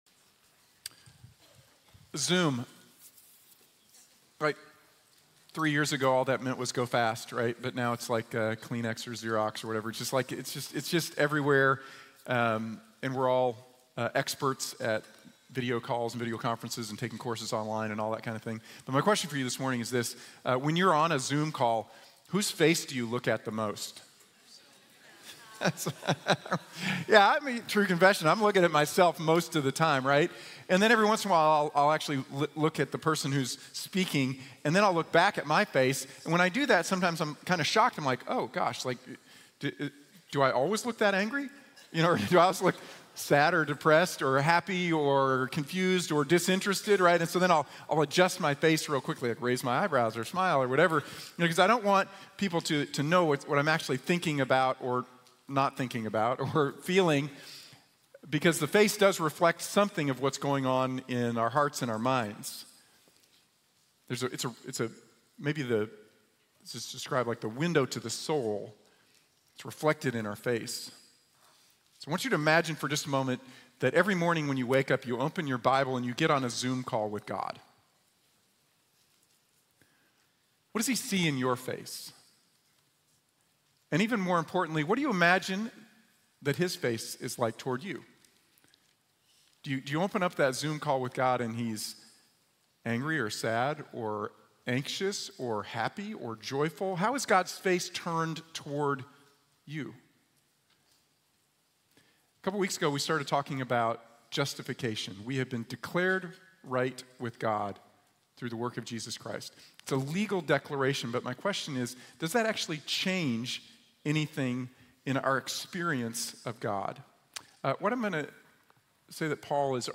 Blessings for the Justified | Sermon | Grace Bible Church